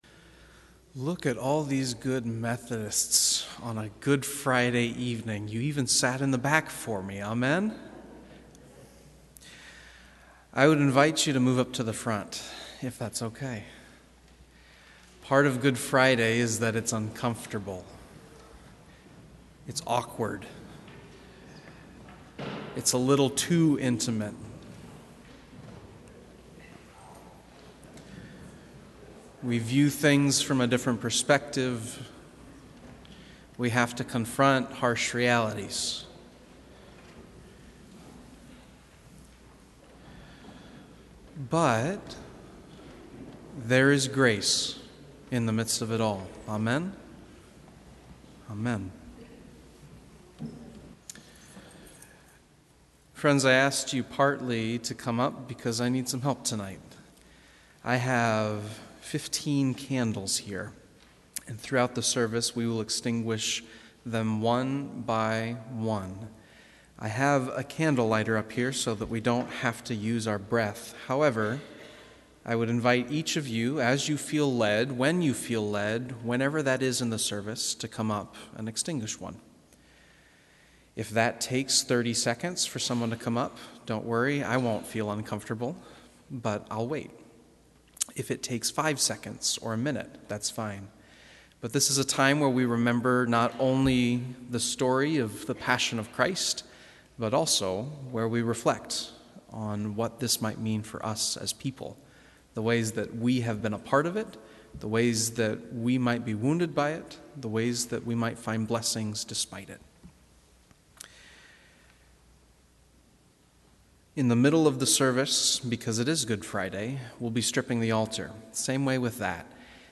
Sermon Audio Files | First United Methodist Church